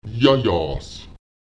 Die letzten Konsonanten des ersten Teils werden nicht gesprochen und der vorangehende Vokal stets kurz:
Lautsprecher yásyás [Èjajaùs] sehr warm, heiß (falsch: [Èjaùsjaùs])